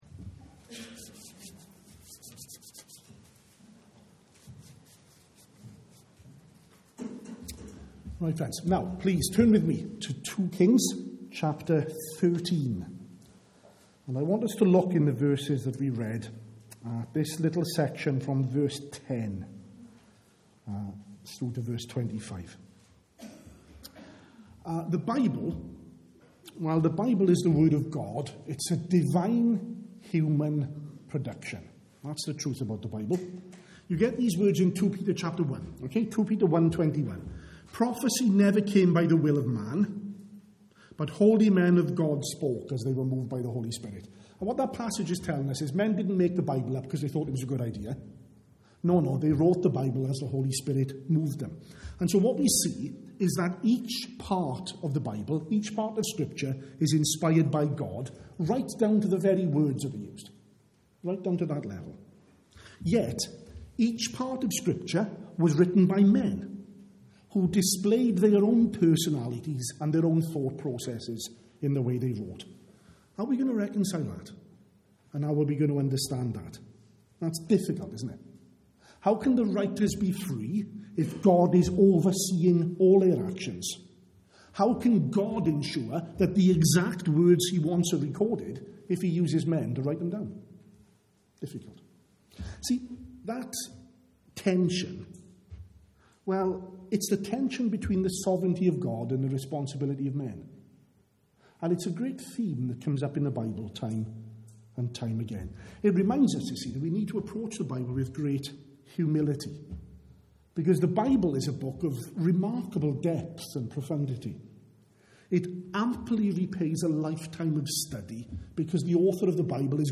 at the evening service.